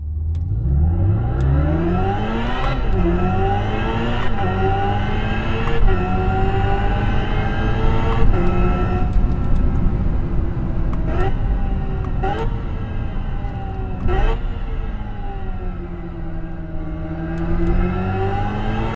Écoutez le doux son de la performance
Appuyez doucement sur l’accélérateur, instantanément le volume sonore monte !
Hyundai_IONIQ_5_N_Sound_Evolution.mp3